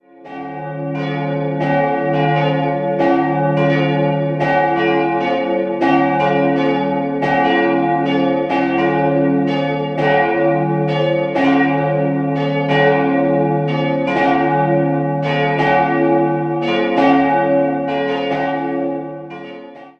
Bei der letzten Innenrenovierung wurde die originale Farbgebung von 1864 wiederhergestellt. 3-stimmiges Geläut: des'-f'-as' Alle drei Eisenhartgussglocken wurden 1922 von der Gießerei Schilling&Lattermann gegossen.